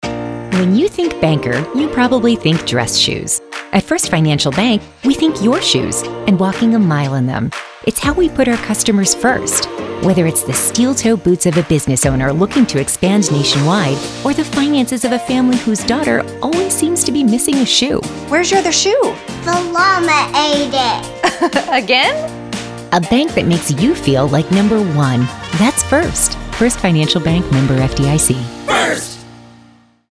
Video :30 Broadcast and OTT :15 Broadcast and OTT :06 Broadcast and OTT OOH Billboards Social Radio :30 Radio, “Putting You First”